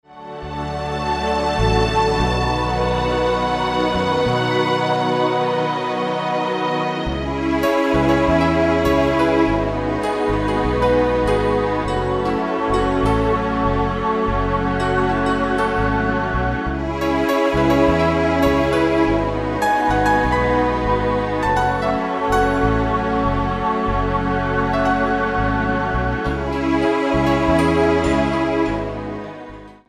Piękna harmonijna muzyka do masżu.